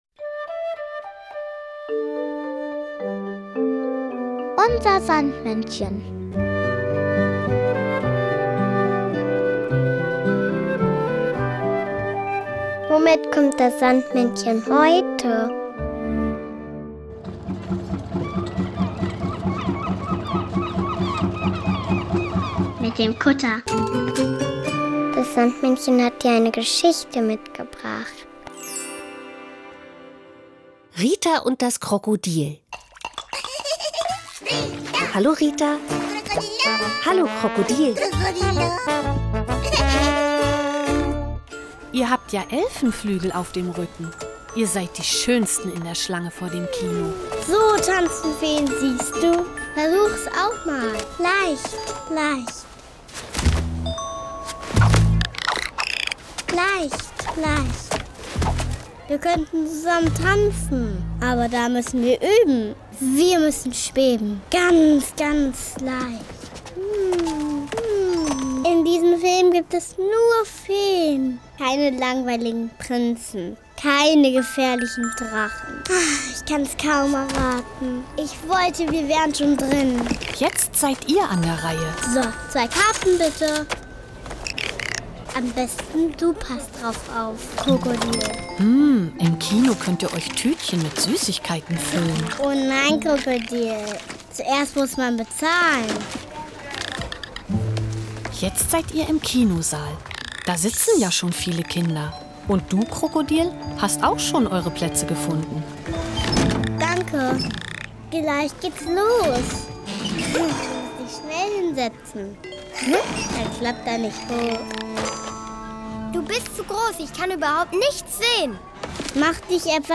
UNSER SANDMÄNNCHEN bringt die Geschichten der beliebten Sandmannserien zum Hören mit.